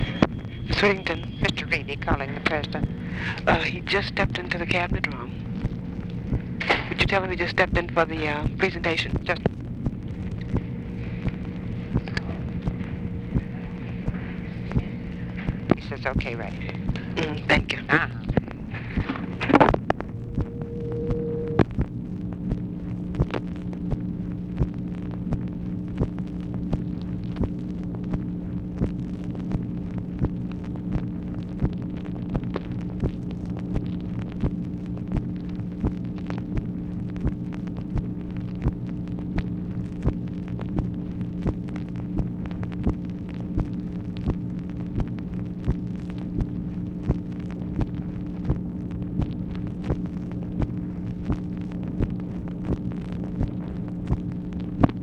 Conversation with TELEPHONE OPERATOR
Secret White House Tapes